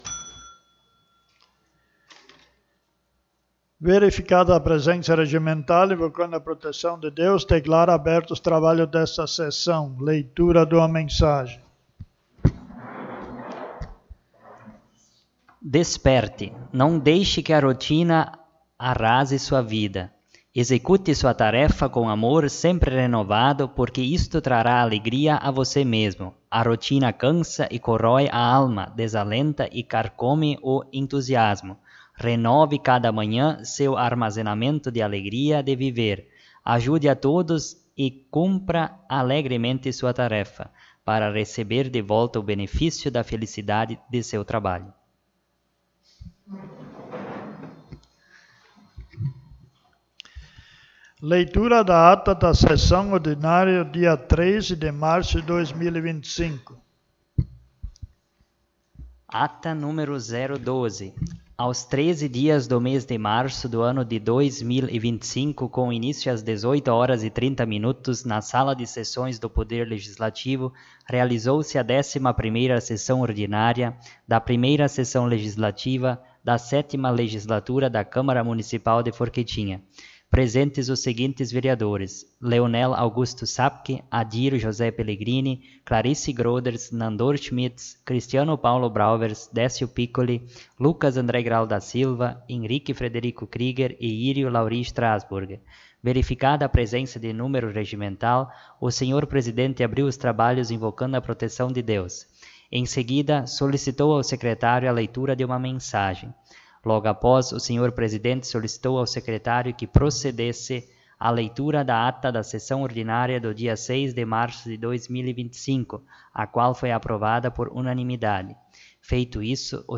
12ª Sessão Ordinária
O espaço da tribuna foi utilizado pela vereadora Clarice Groders.